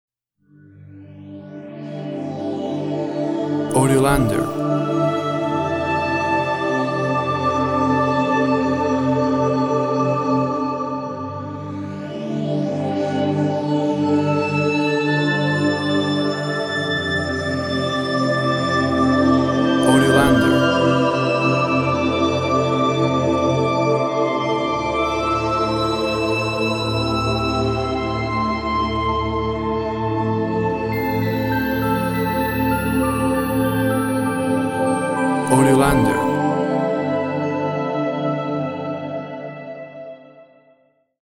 Foggy/misty synth atmosphere.
Tempo (BPM) 68